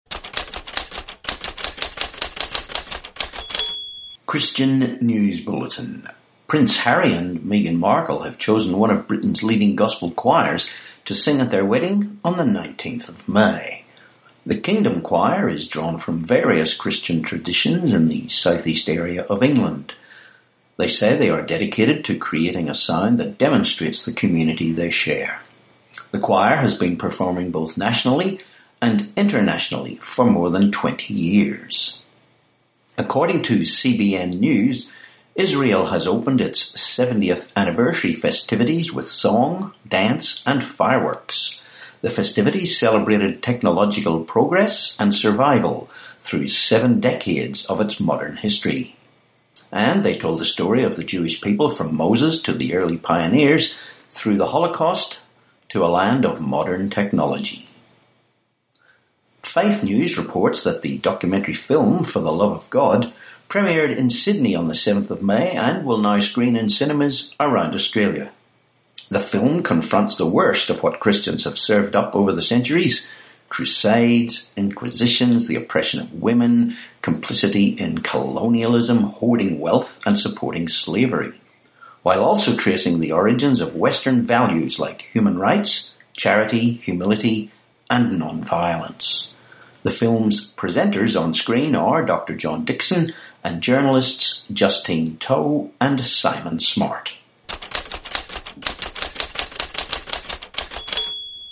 13May18 Christian News Bulletin